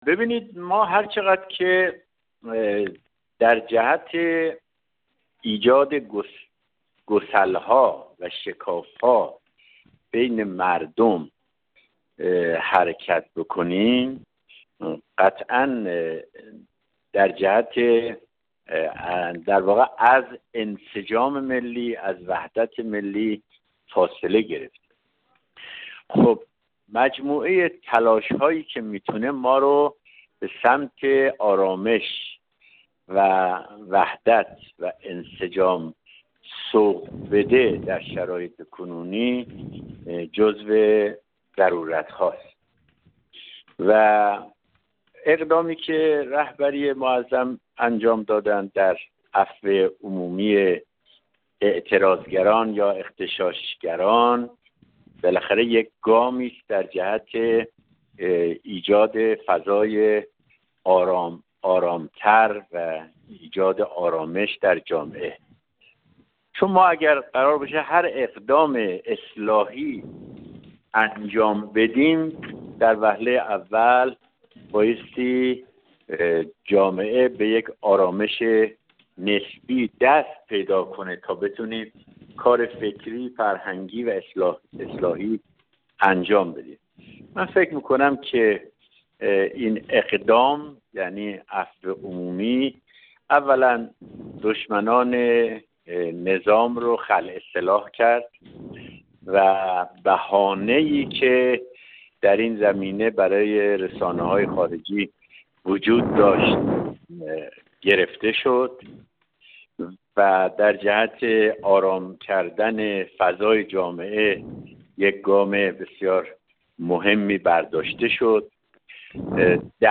محمدجواد آرین‌منش، جامعه‌شناس و عضو پیشین کمیسیون فرهنگی مجلس
گفت‌وگو